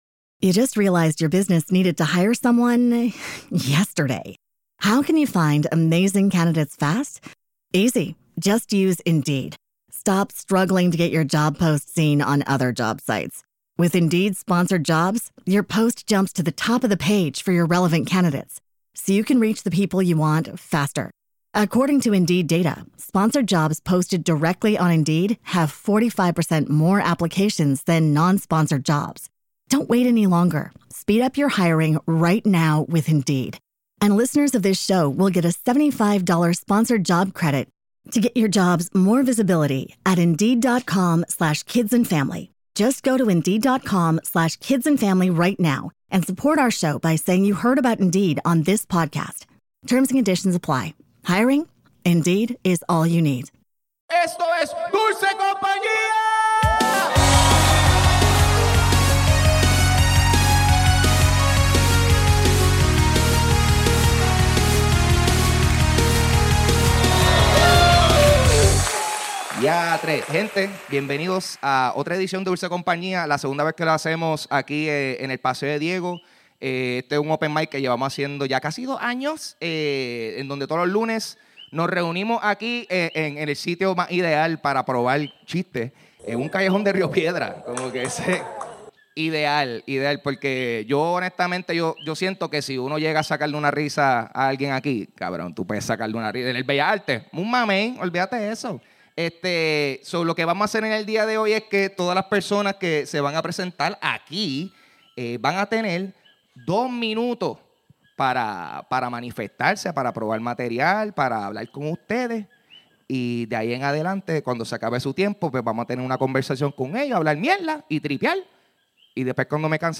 Por casi dos años llevamos haciendo un Open Mic en el Paseo de Diego en Rio Piedras para personas de todo nivel practicar el arte del stand up. Bienvenidos al callejón de la comedia.